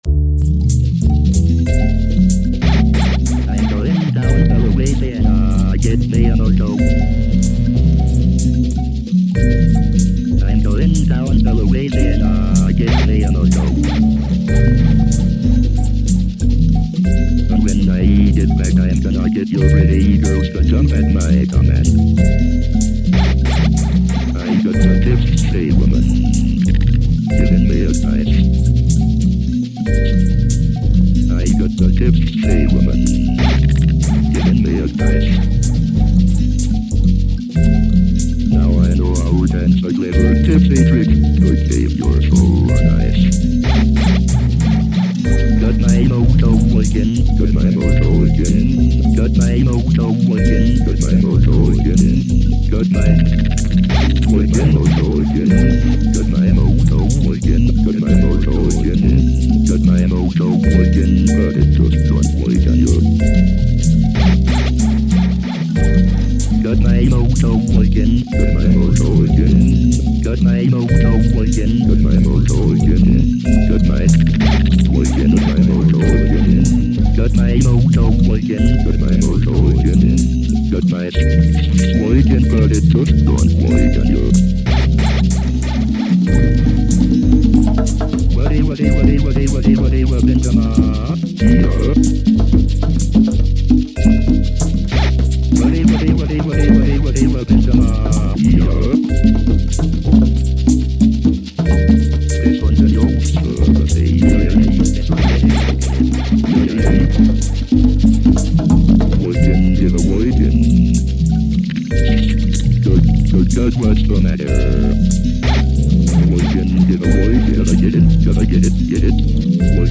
File under: Avantgarde